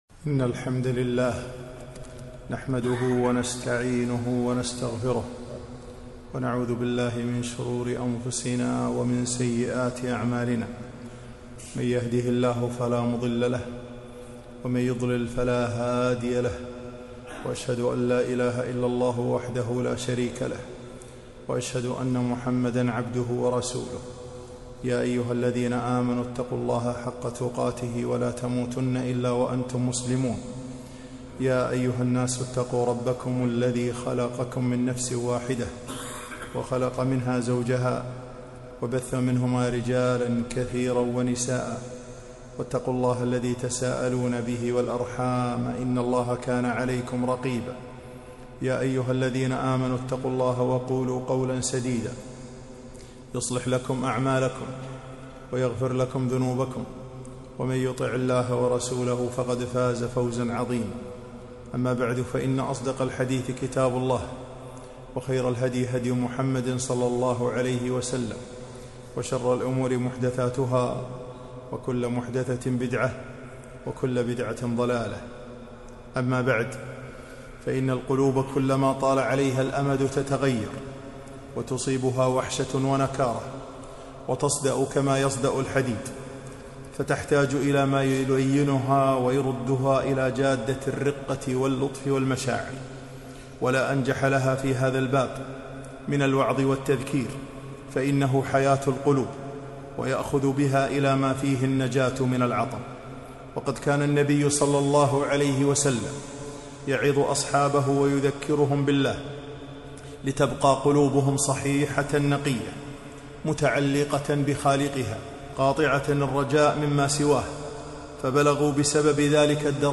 خطبة - الحاجة إلى المواعظ